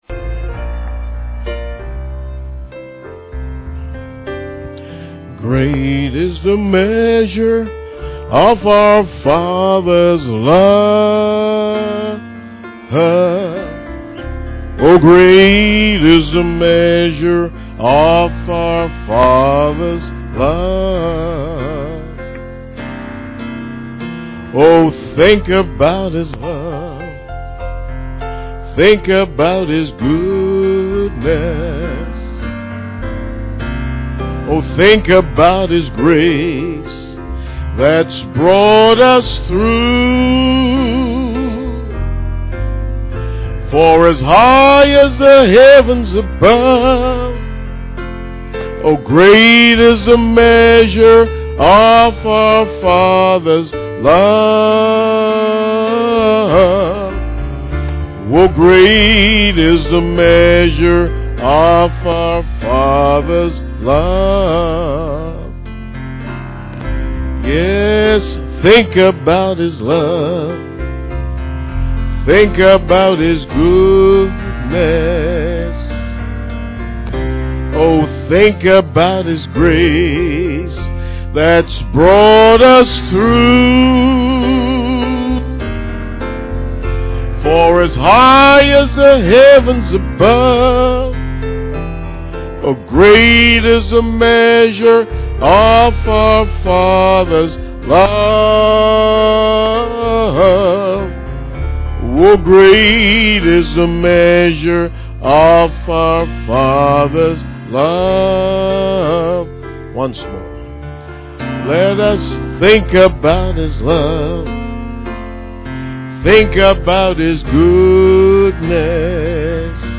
Guest Minister Passage: Hebrews 9:1-10 Service Type: Sunday Morning %todo_render% « Ephesians Chapter 5